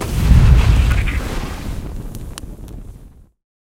magic_fire.ogg